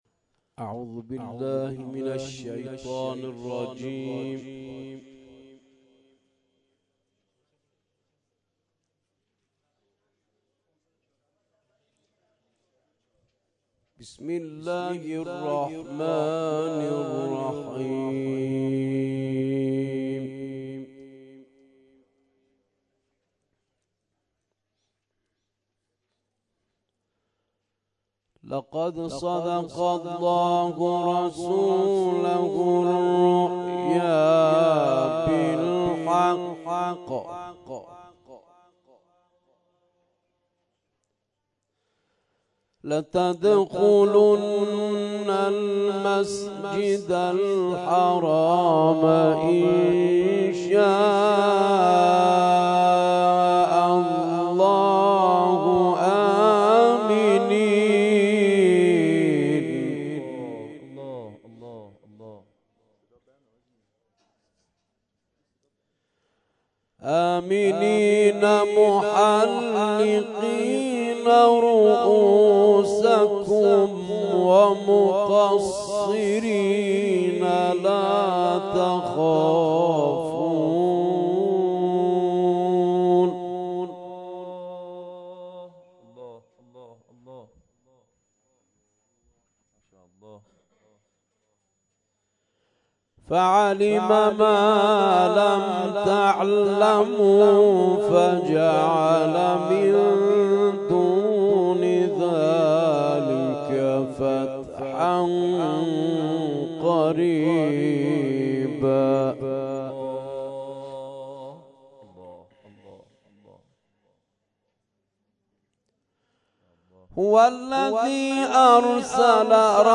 نخستین تلاوت